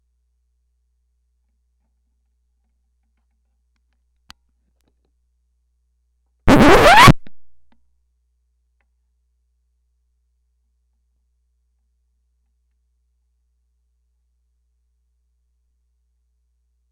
record scratch
needle-drag oops record scratch sound effect free sound royalty free Memes